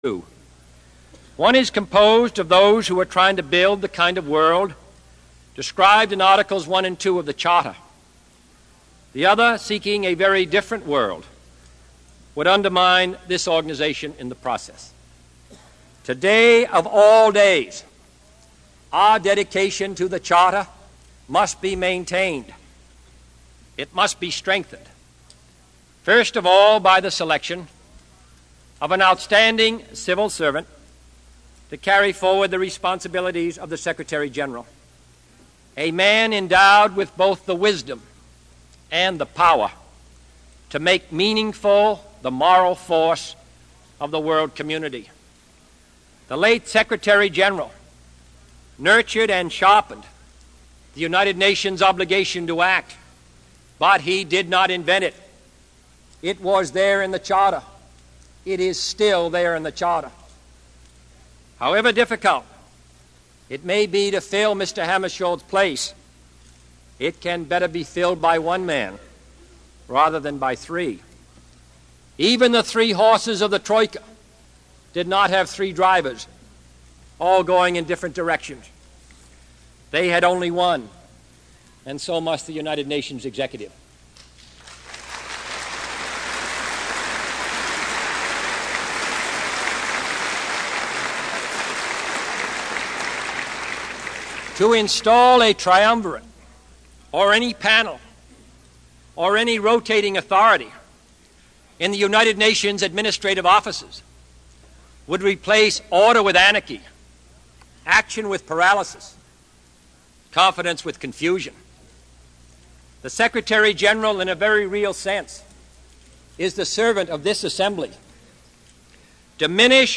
John F Kennedy Address to United Nations 2
Tags: John F. Kennedy John F. Kennedy Address United Nations John F. Kennedy speech President